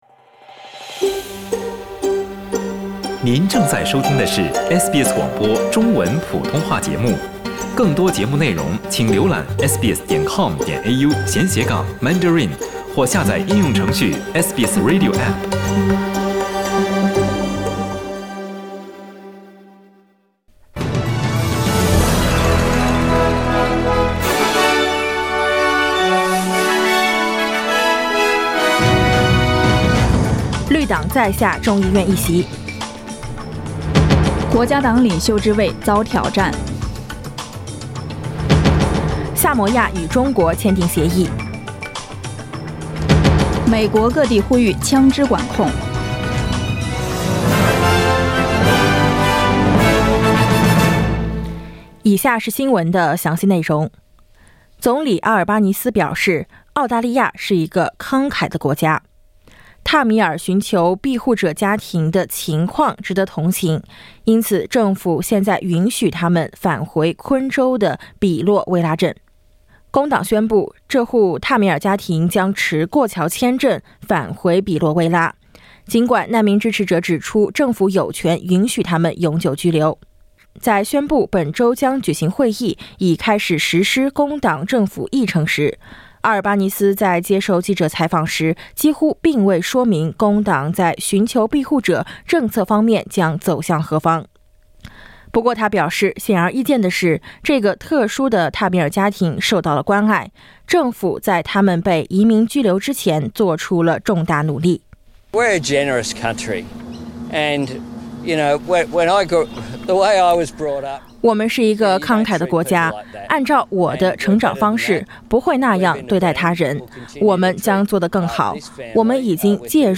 SBS早新闻（5月29日）